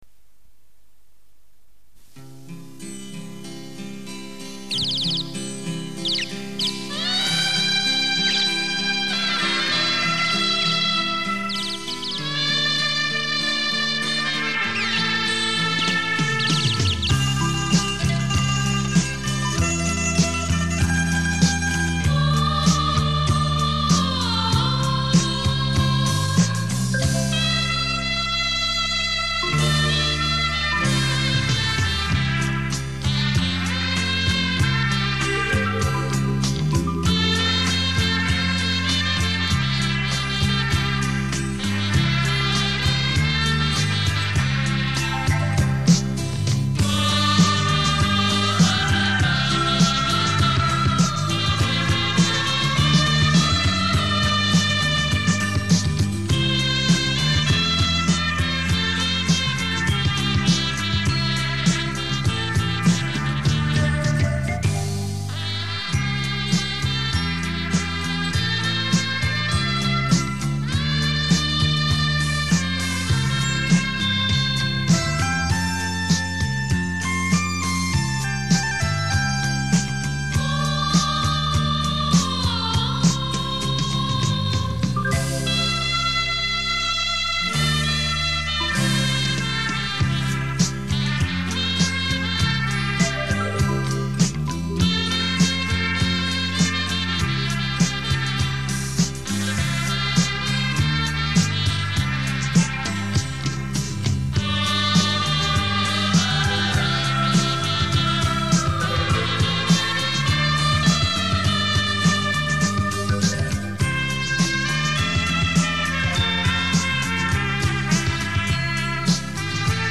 类型：纯音乐